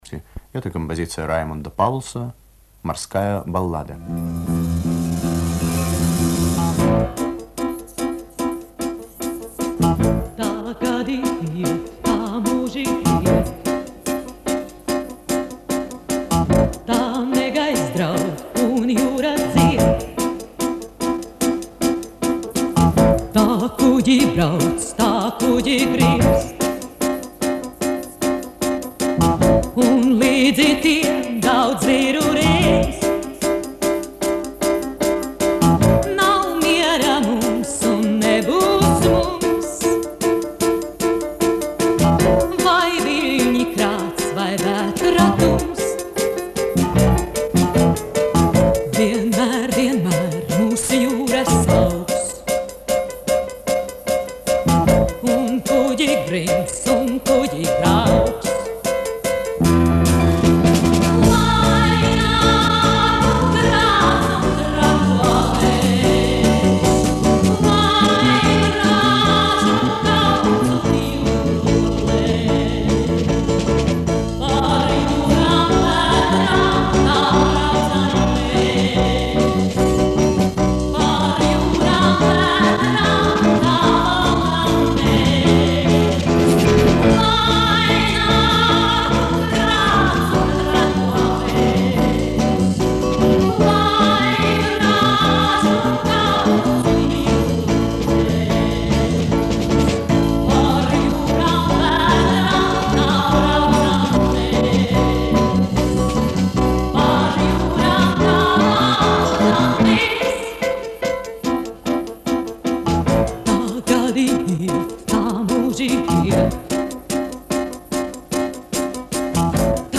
Запись с радиоэфира прилагается.